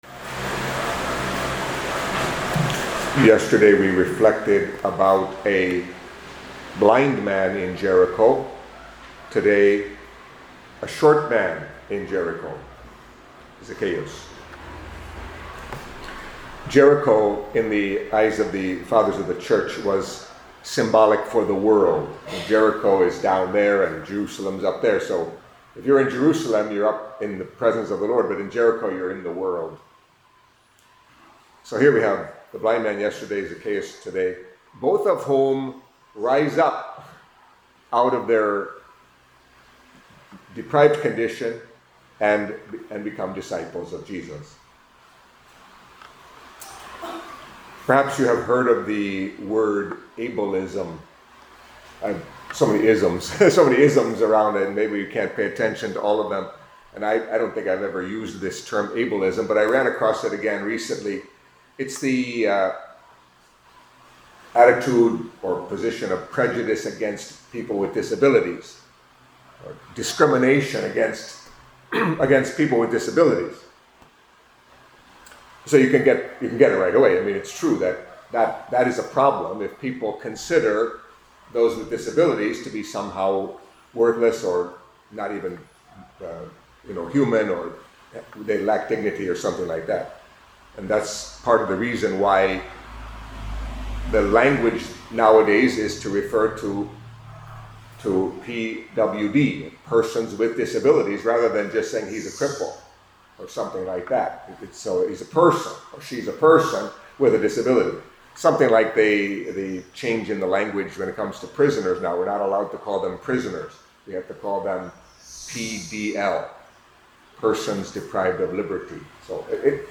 Catholic Mass homily for Tuesday of the Thirty-Third Week in Ordinary Time